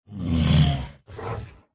Minecraft Version Minecraft Version snapshot Latest Release | Latest Snapshot snapshot / assets / minecraft / sounds / mob / polarbear / idle4.ogg Compare With Compare With Latest Release | Latest Snapshot